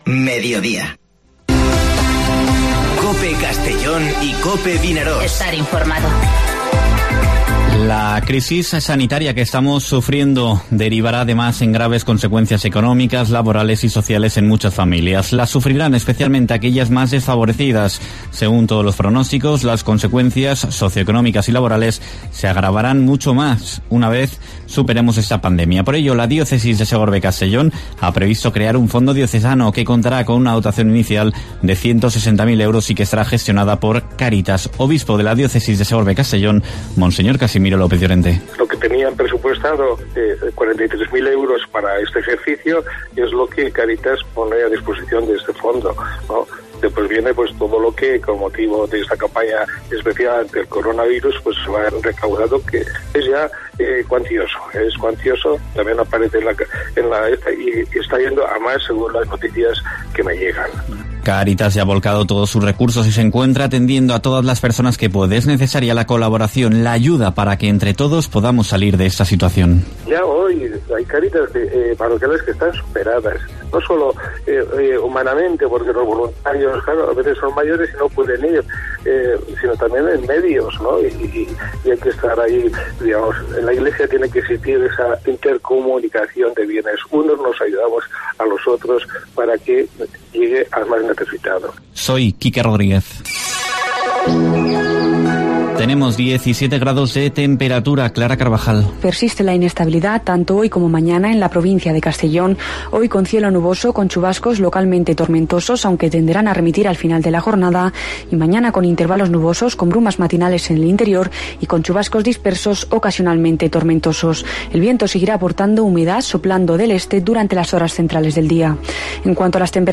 Informativo Mediodía COPE en la provincia de Castellón (17/04/2020)